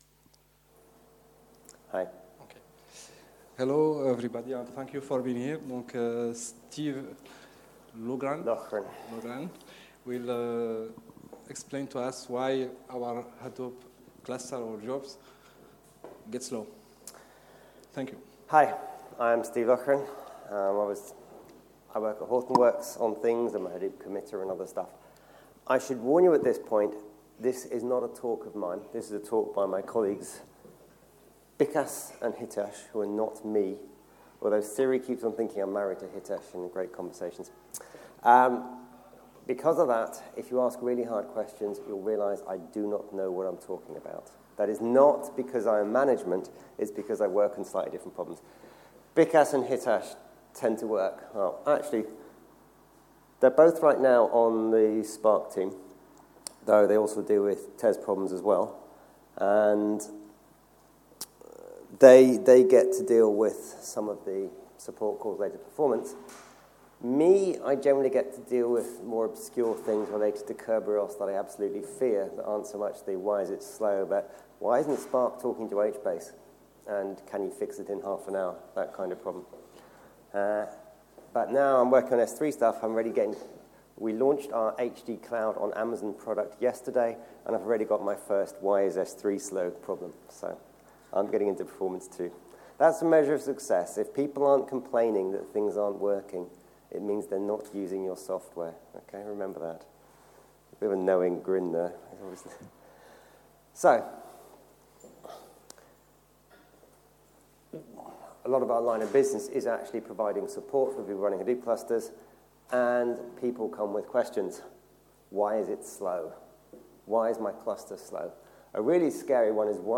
Apache Big Data Seville 2016: Why is My Hadoop Cluster Slow?